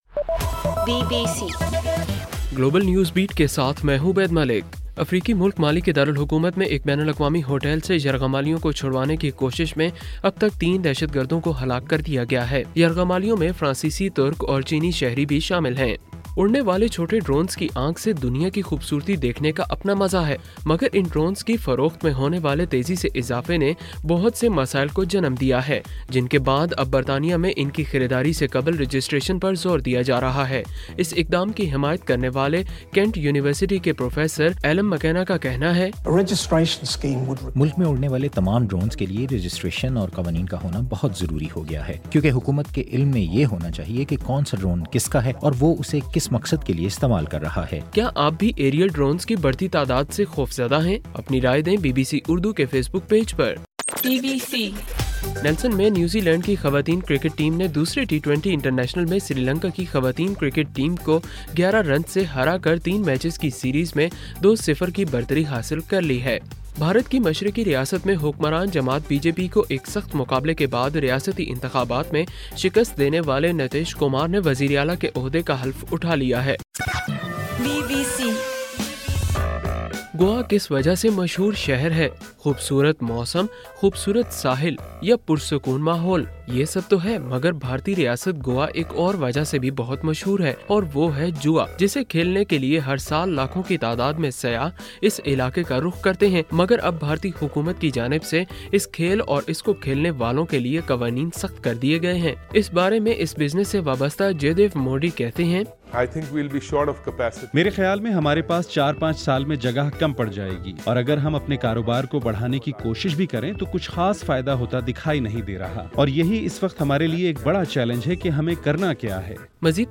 نومبر 20: رات 8 بجے کا گلوبل نیوز بیٹ بُلیٹن